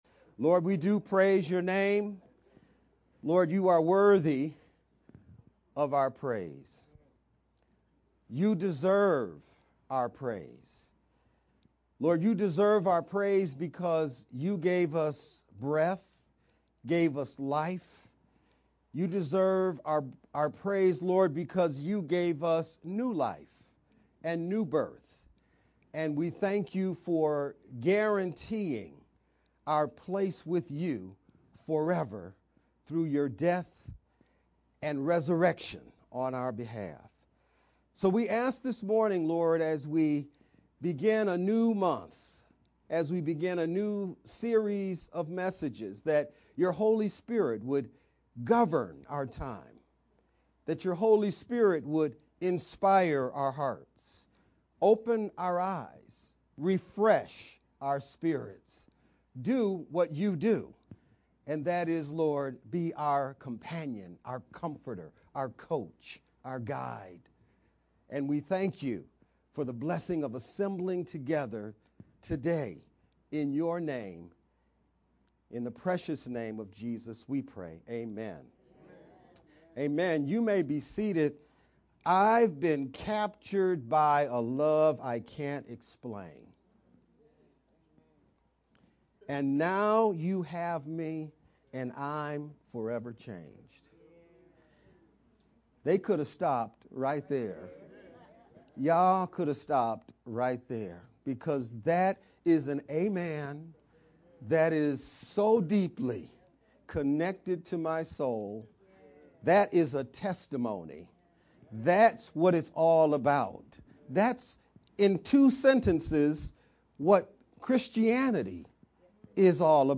Sept-1st-VBCC-edited-sermon-only_Converted-CD.mp3